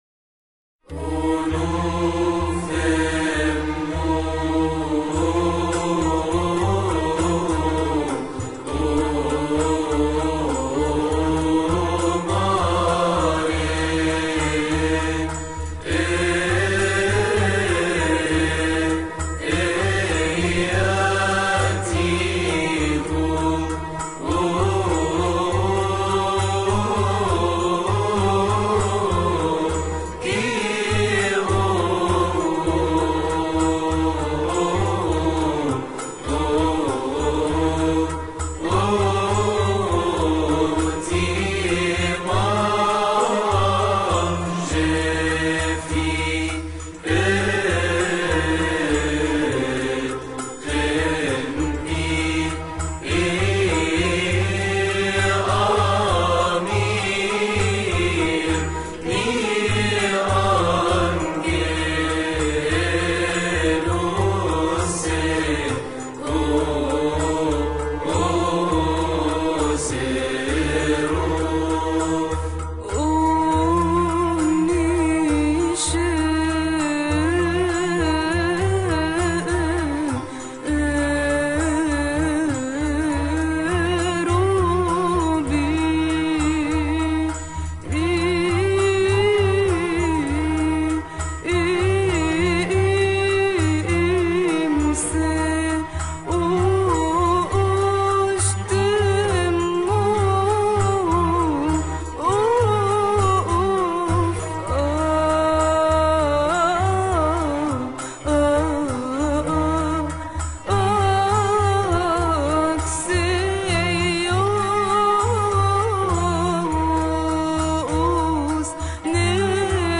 استماع وتحميل لحن لحن اوموف اممو من مناسبة nhdet-al3dra